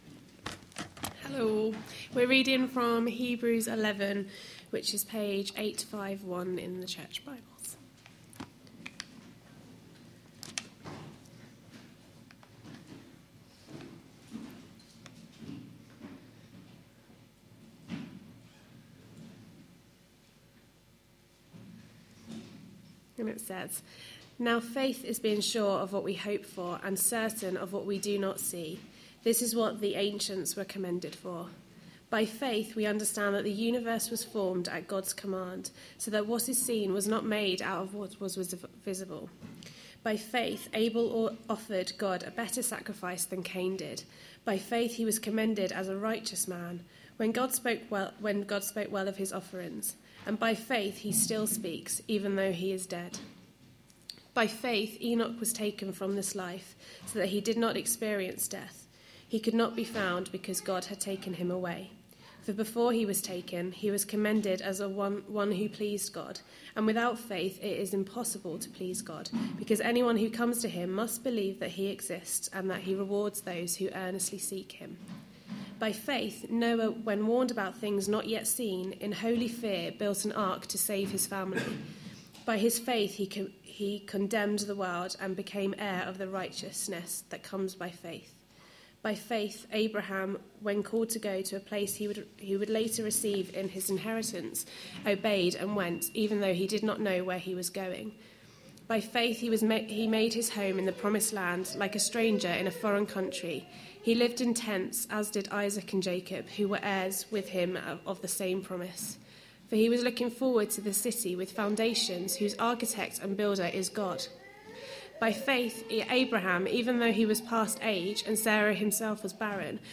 A sermon preached on 1st March, 2015, as part of our Hebrews series.